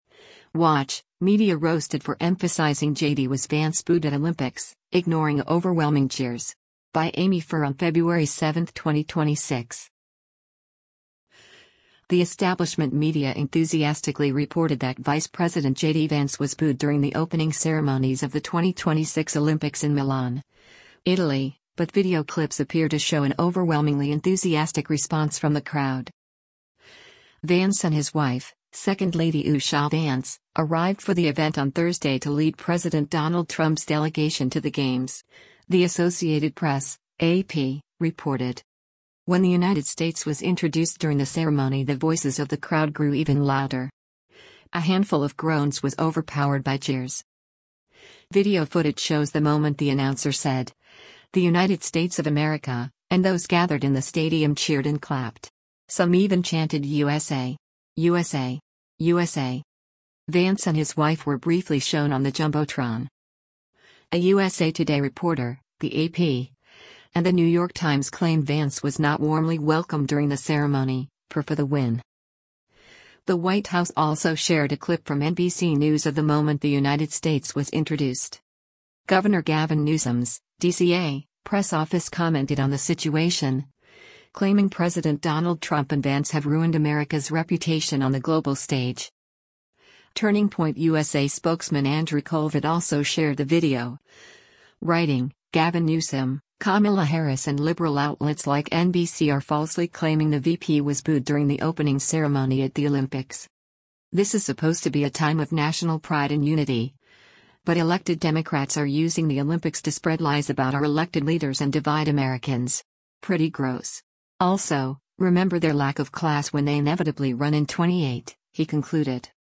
When the United States was introduced during the ceremony the voices of the crowd grew even louder. A handful of groans was overpowered by cheers.
Video footage shows the moment the announcer said, “The United States of America,” and those gathered in the stadium cheered and clapped. Some even chanted “USA! USA! USA!”